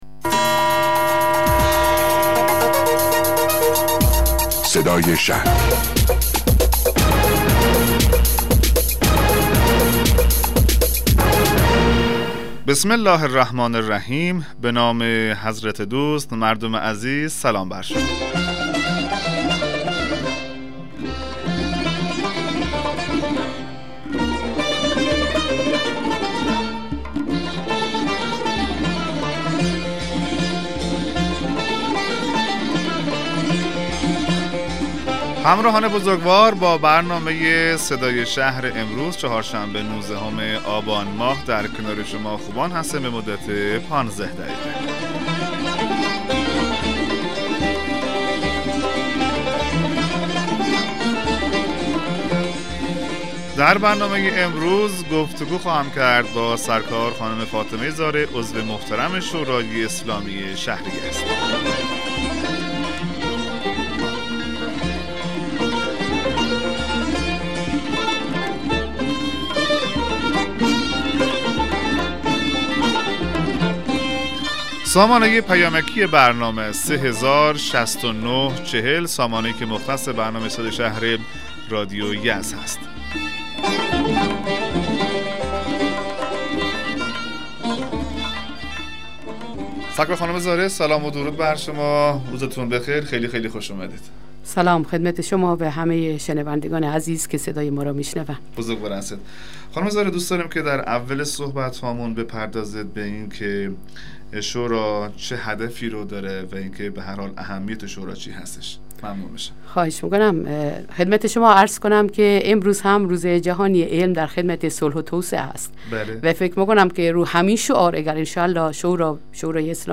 مصاحبه رادیویی برنامه صدای شهر با حضور فاطمه زارع عضو شورای اسلامی شهر یزد